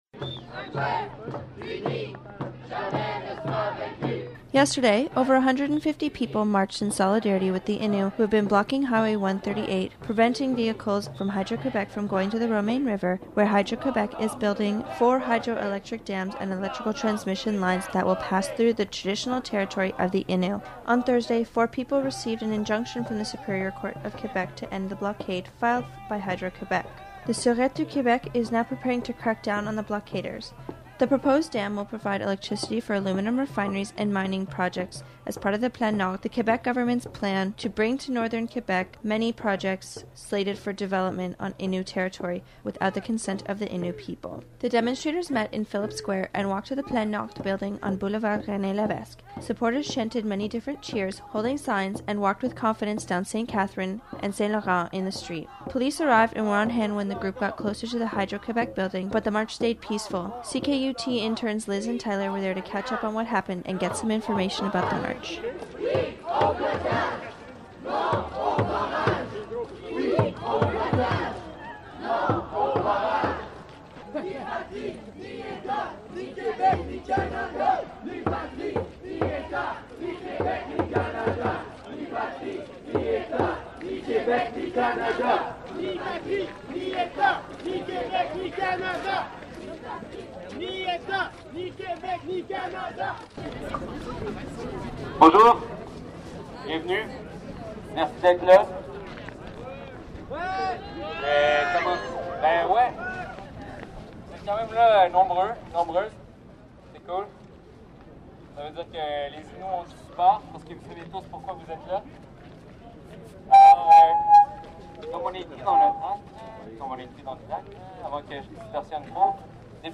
CKUT Native Solidarity News: Innu Blocade Demonstration March 13th 2012
final_mixdowninnu_march.mp3